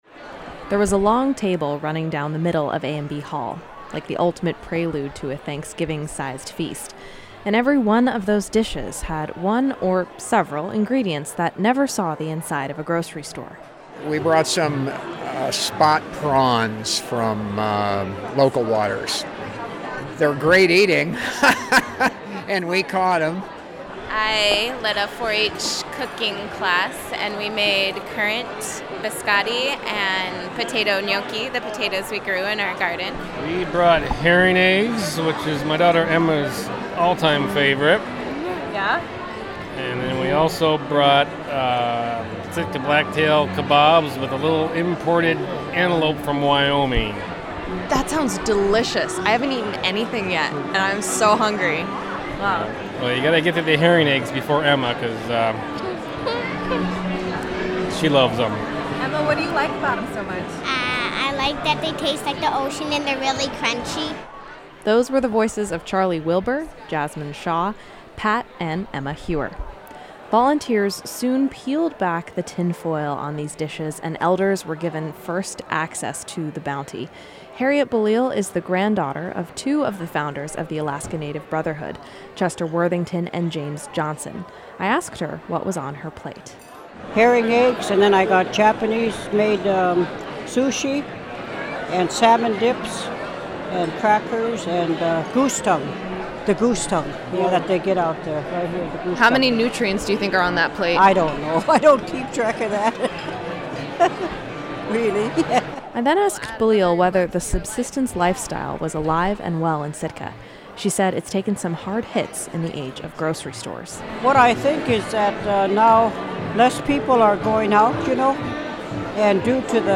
The Wild Foods Potluck, hosted by the Sitka Conservation Society at ANB Hall, brought over 150 Sitkans together for an evening of sharing – and taste testing – wild foods.